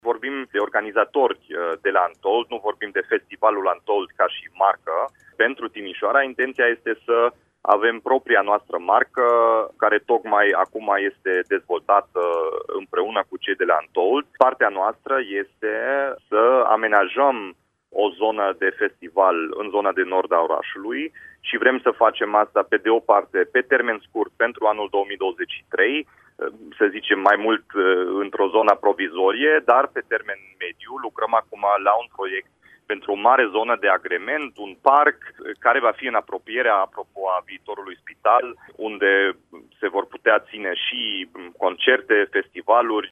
Municipalitatea a identificat, deja, un teren, în zona de nord, unde poate fi amenajat un spațiu care să găzduiască evenimente de mare amploare, a precizat, la Radio Timișoara, primarul Dominic Fritz: